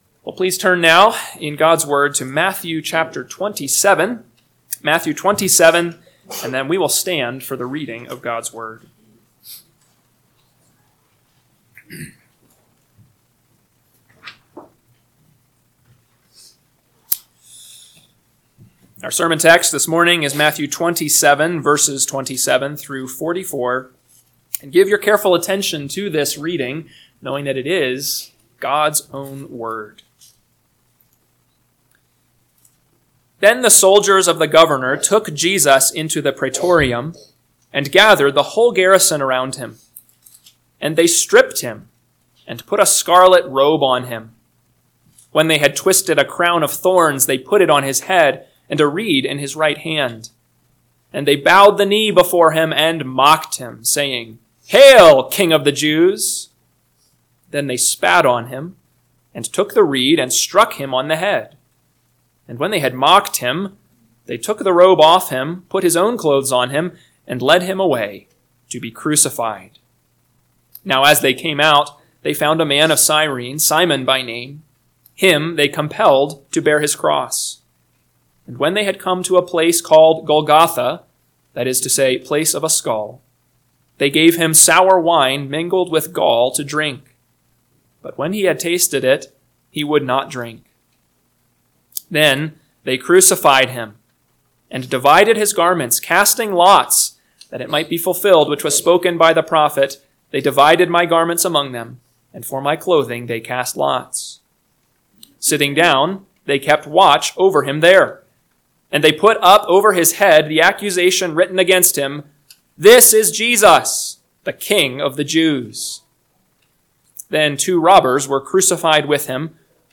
AM Sermon – 4/27/2025 – Matthew 27:27-44 – Northwoods Sermons